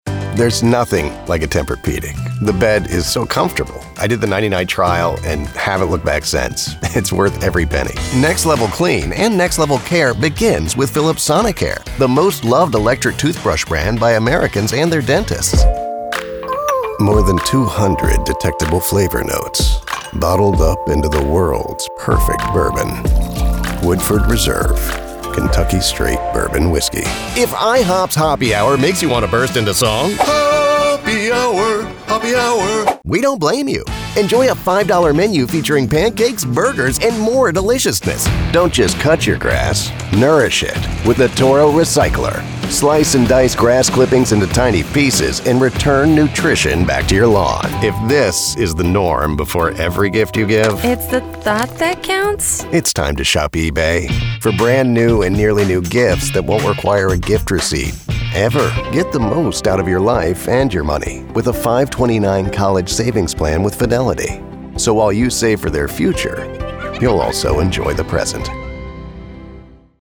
Commercial Demo
English (North American)
Southern (Georgia)
Middle Aged